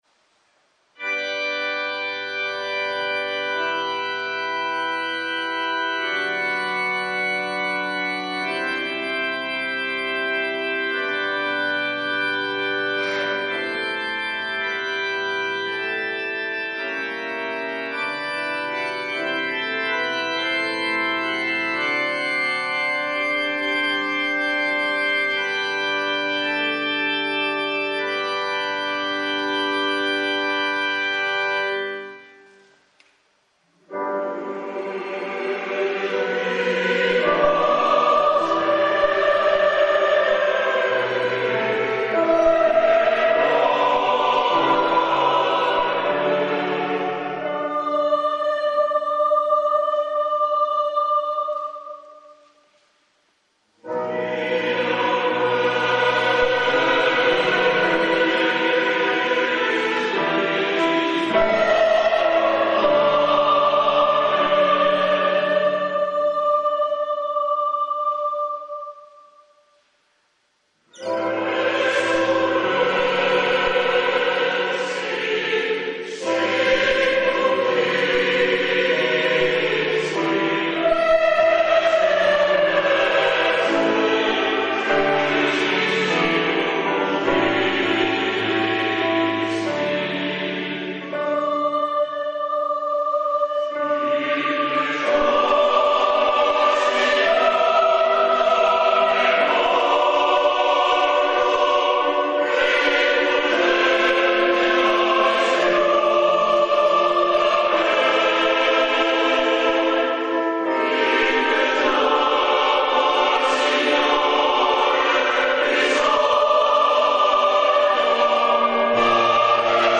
Coro Polifonico Beato Jacopo da Varagine - Varazze
N.b.: il file comprende le due esecuzioni che sono consecutive. Brano eseguito con "A.L.I.V.E. Chorus"
Coro dell'Accademia di Musica Lirica di Verona.